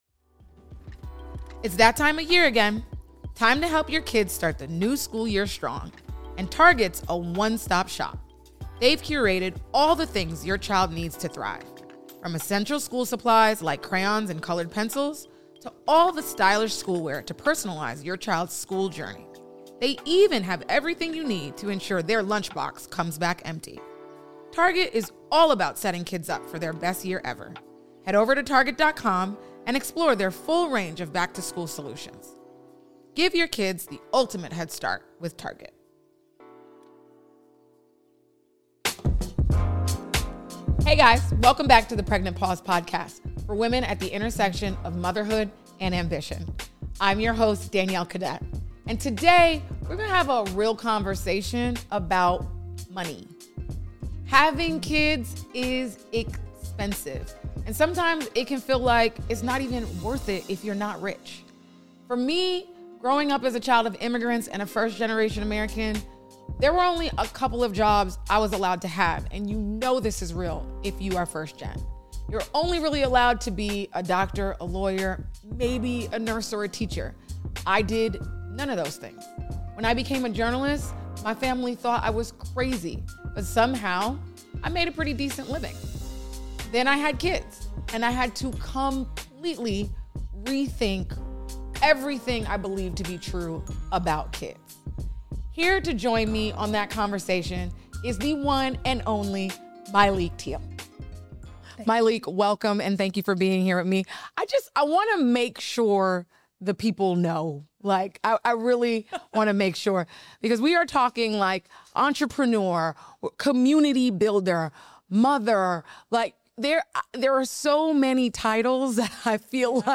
Guest Interview